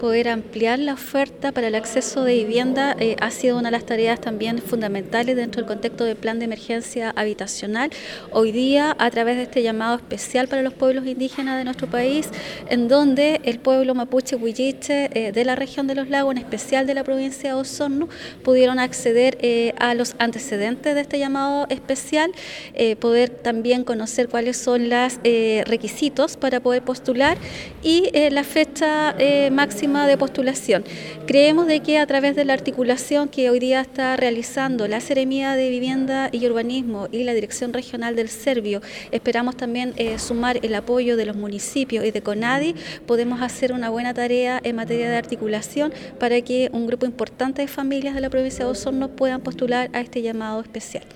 Esto responde a los distintos requerimientos que se enmarcan en el Plan de Emergencia Habitacional, lo que se ha logrado gracias al trabajo intersectorial que se ha realizado para este programa según declaró la Delegada Presidencial Provincial Claudia Pailalef.